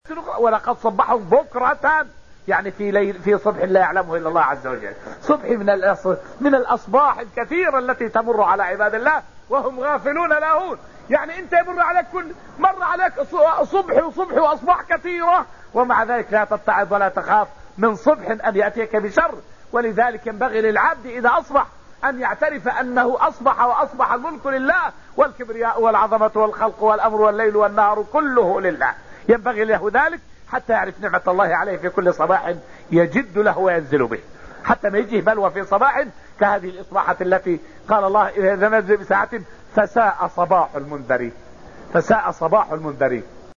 أحد حلقات البرنامج الإذاعي "دروس من الحرمين الشريفين" الذي كانت تبثه إذاعة القرآن الكريم من المملكة العربية السعودية، وتكلم الشيخ رحمه الله في هذا الدرس حول بعض نعم الله التي تحيط بنا ونستمتع بها في حياتنا وكيفية شكر هذه النعم وأن الإنسان مهما بذل من جهد ليعد أو يحصي هذه النعم فلن يقدر على ذلك.